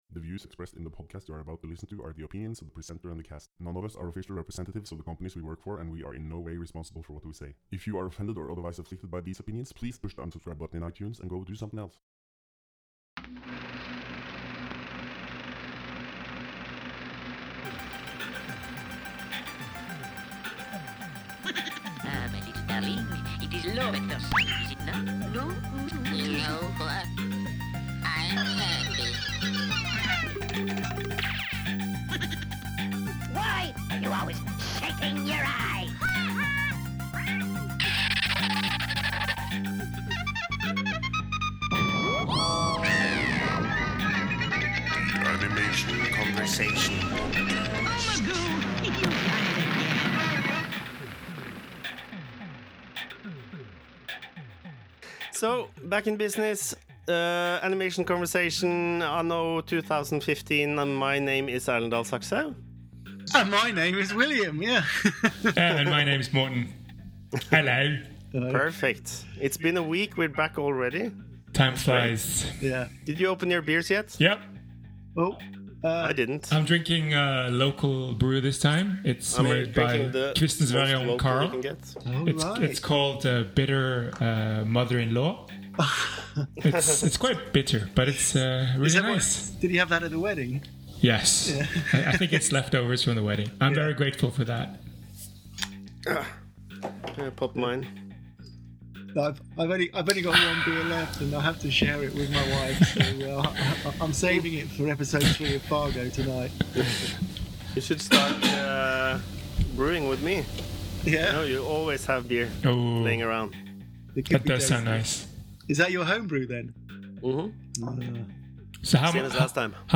Still warming up for the new season, but we are definitely back! Sorry about the sound quality.